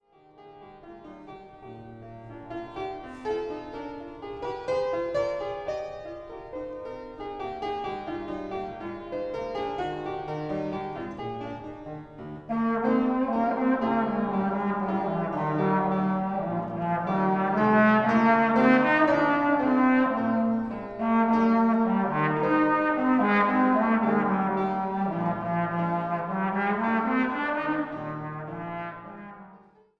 Klassische Posaune
Hammerklavier